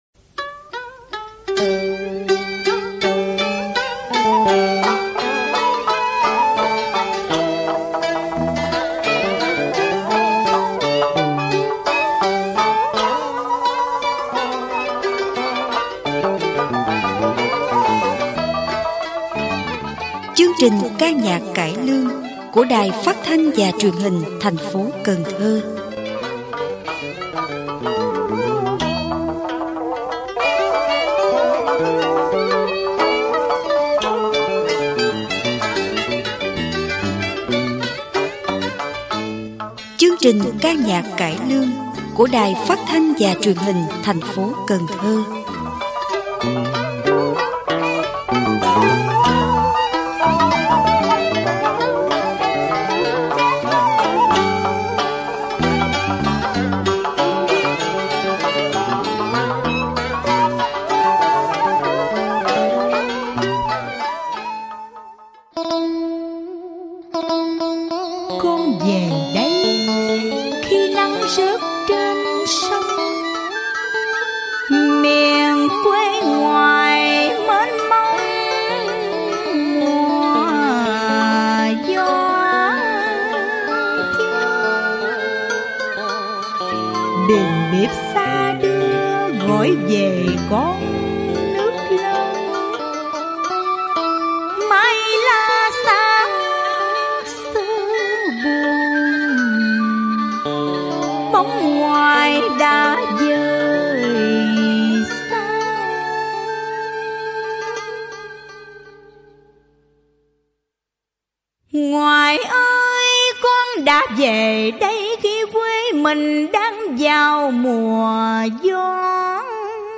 Ca nhạc cải lương: Dòng sông quê ngoại